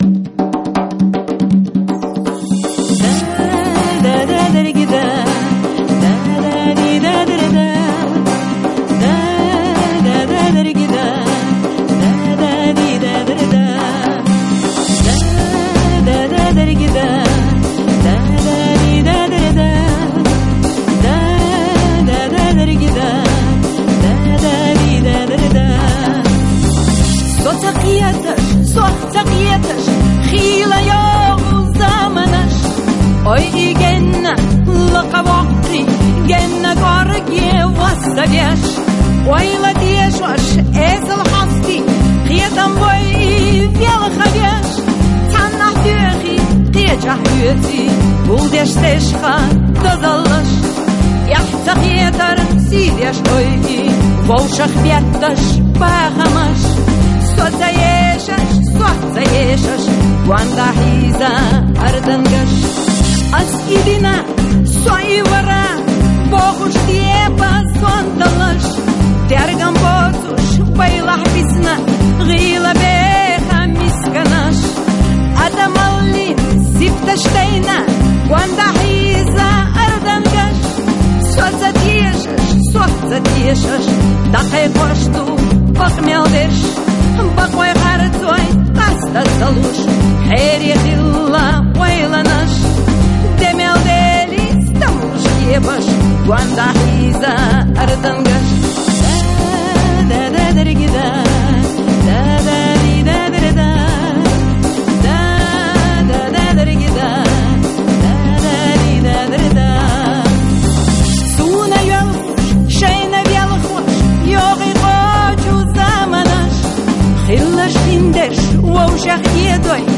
протестан эшарш а йохуш